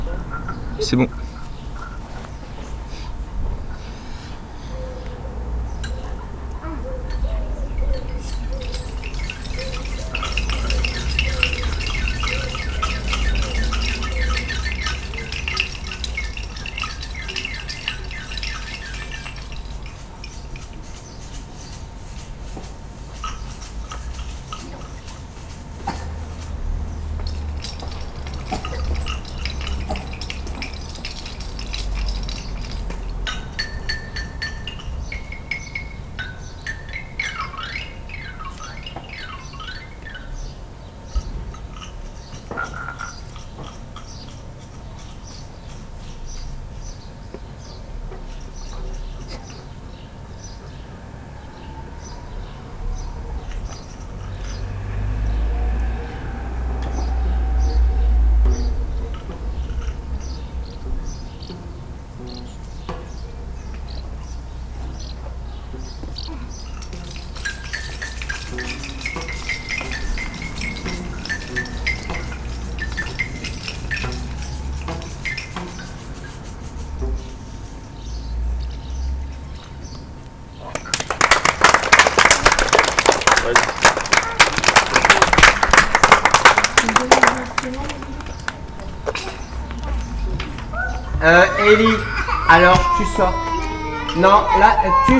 -les bâtons de pluie
-les crécelles
-les élastophones
-les xylophone
-les maracas
Ensuite nous avons expérimenté, manipulé, produits des sons en essayant chaque instrument.
Pour finir, chaque groupe a joué un petit concert avec les instruments.